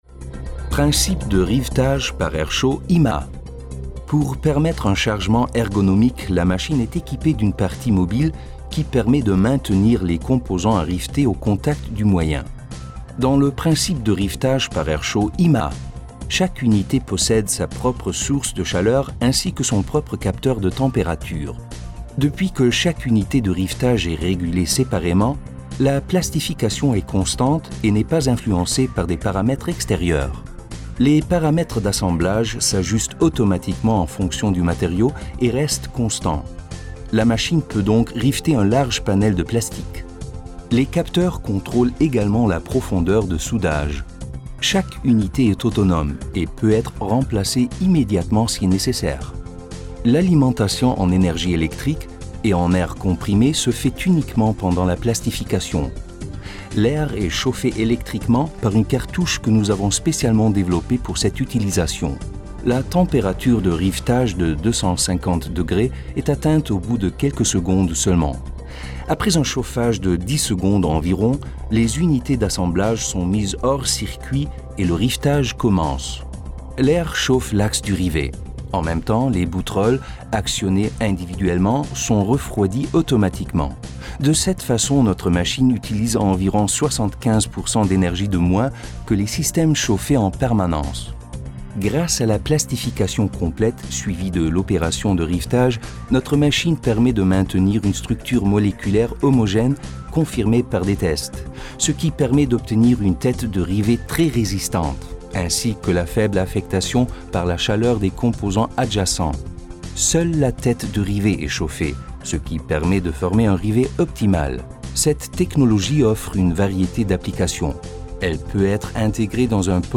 Französische Vertonung:
3con-ImaA-Heissluftfuegen-franzoesich.mp3